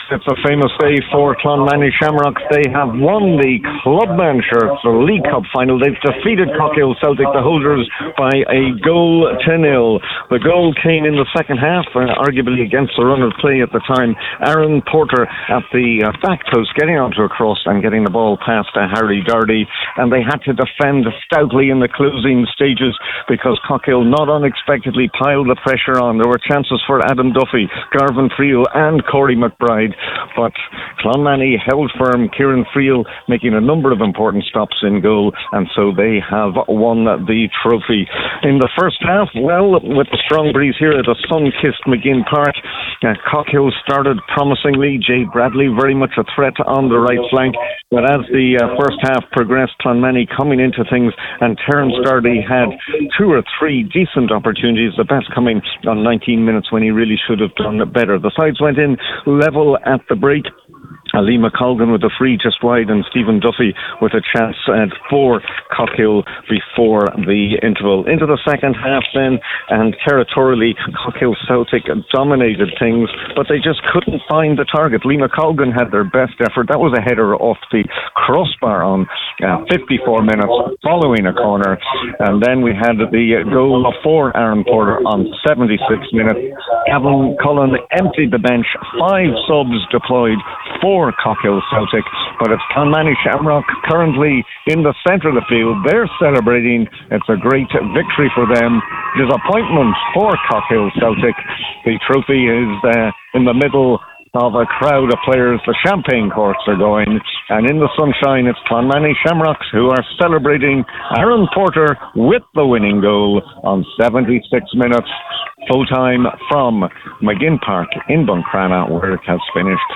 was in Buncrana for Highland Radio Sunday Sport…